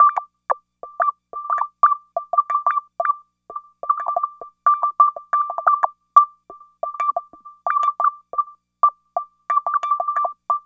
Synth 05.wav